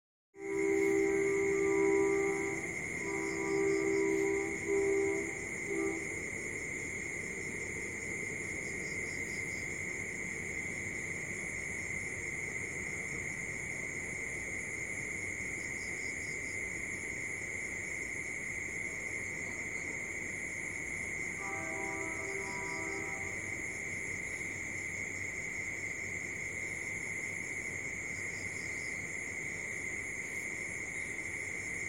夜晚的蟋蟀声和火车声
标签： 性质 板球 昆虫
声道立体声